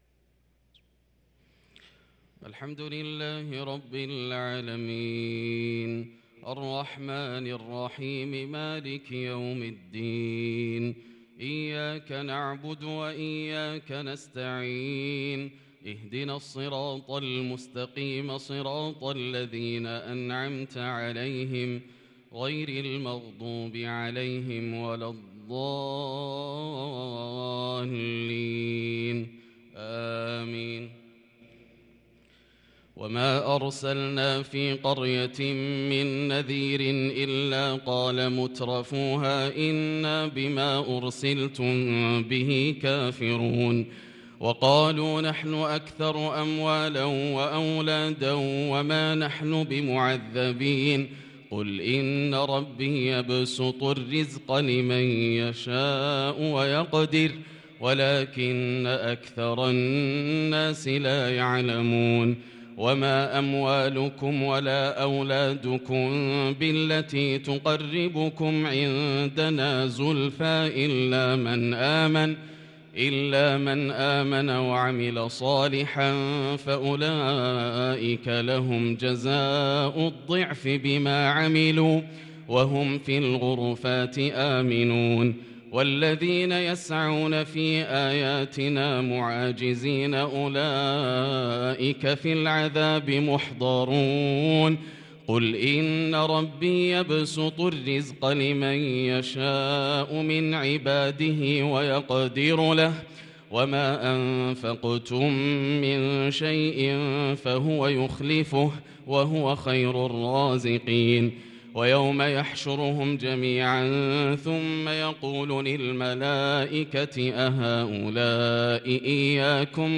صلاة العشاء للقارئ ياسر الدوسري 26 شعبان 1443 هـ
تِلَاوَات الْحَرَمَيْن .